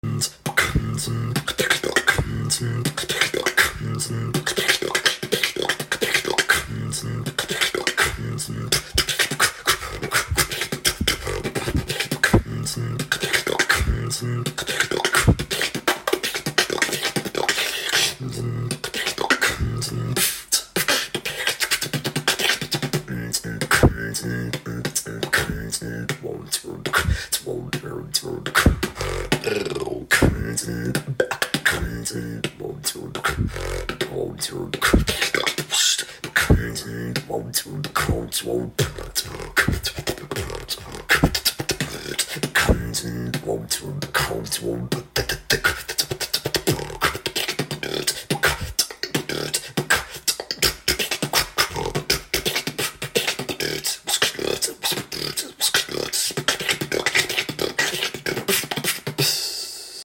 Beatbox!!